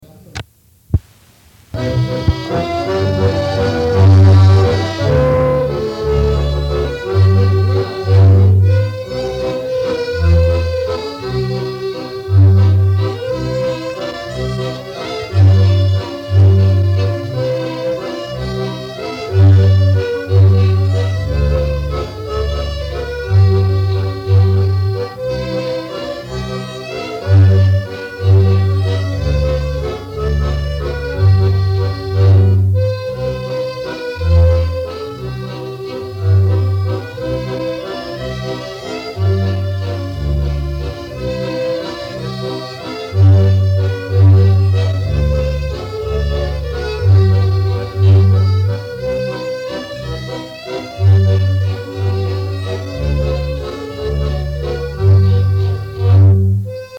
Instrumentalny 20 – Żeńska Kapela Ludowa Zagłębianki
Nagranie archiwalne